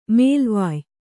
♪ mēlvāy